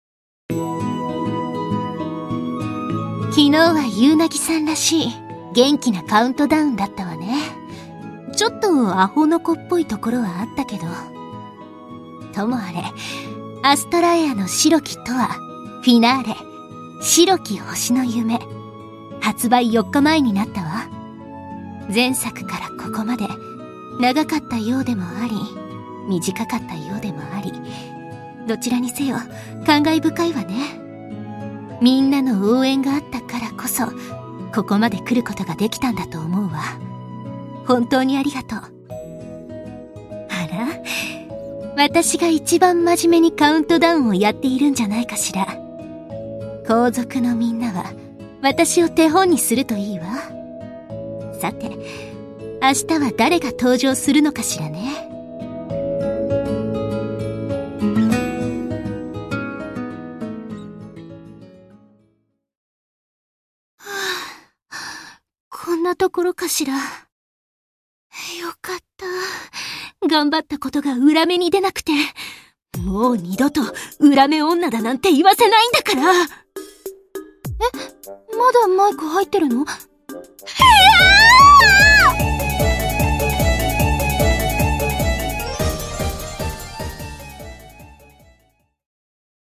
『アストラエアの白き永遠 Finale』 発売4日前カウントダウンボイス（琴里）を公開